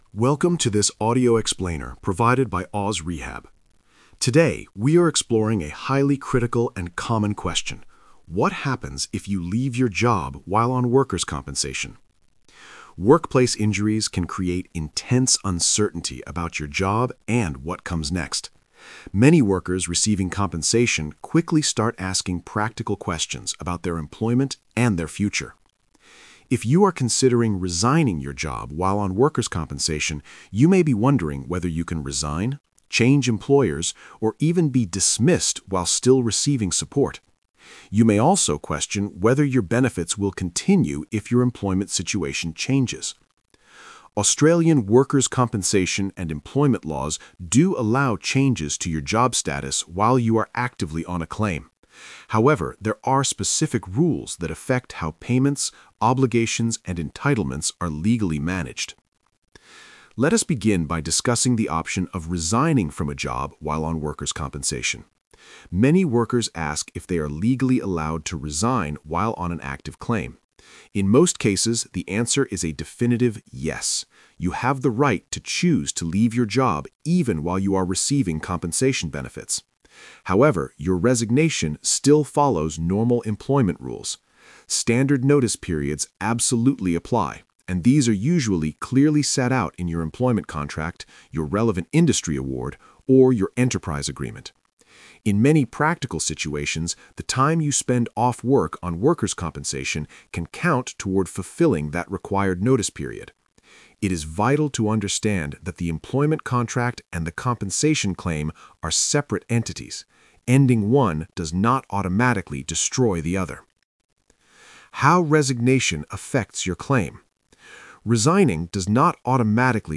Single-host narration